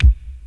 Помогите сделать бочку
Как с помощью sylenth1 например можно получить именно такую короткую, а не с длинным спадом (подобно бочкам TR909)?